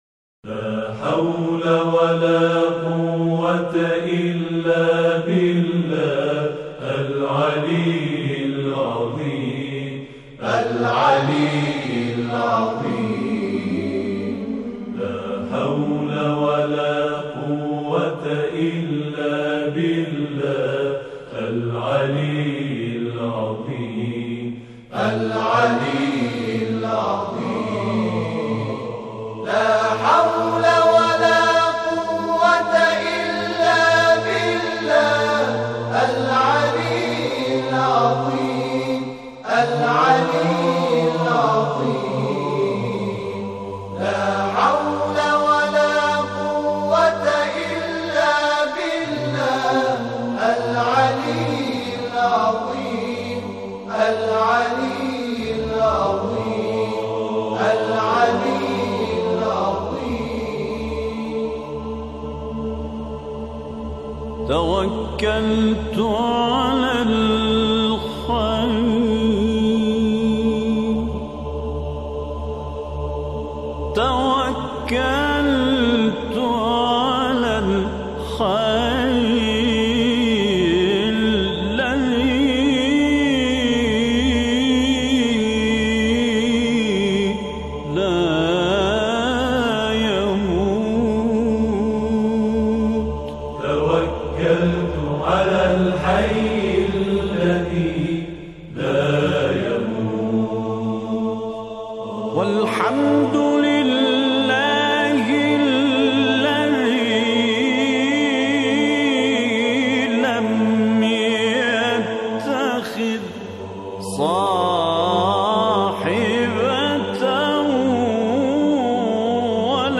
مناجات
با جمع خوانی تعدادی از جمعخوانان به صورت آکاپلا ساخته شده